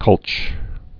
(kŭlch)